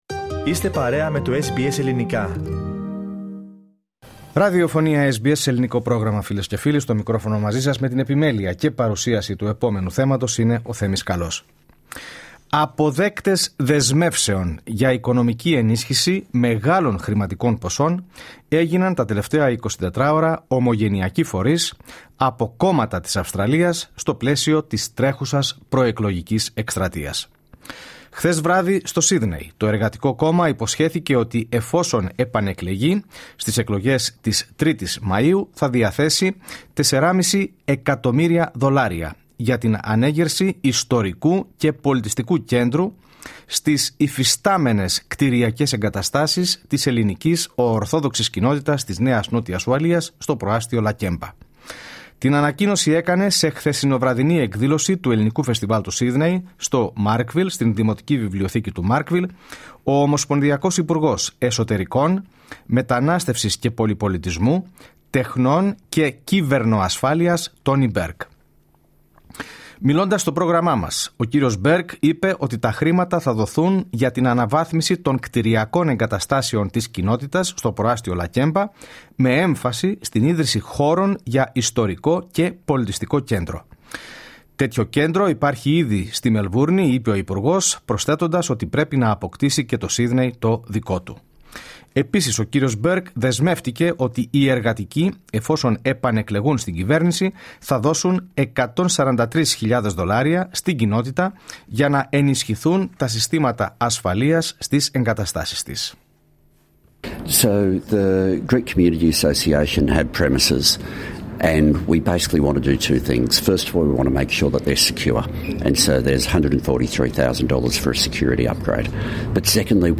LISTEN TO Με εκατομμύρια προσεγγίζουν ομογενειακούς φορείς τα κόμματα εν’ όψει των εκλογών 06:51 Μιλώντας στο πρόγραμμά μας, SBS Greek, ο κ. Burke είπε ότι τα χρήματα θα δοθούν για την αναβάθμιση των κτιριακών εγκαταστάσεων της Κοινότητας στο προάστειο Lakemba με έμφαση στην ίδρυση χώρων για Ιστορικό και Πολιτιστικό Κέντρο.